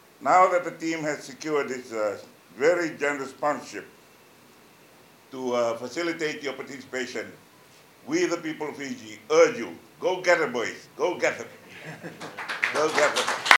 Former player, FRU Trustee and Prime Minister Sitiveni Rabuka at the announcement at Tanoa Plaza
Former player, FRU Trustee and Prime Minister Sitiveni Rabuka while making the announcement says the ball is now in the Flying Fijians court.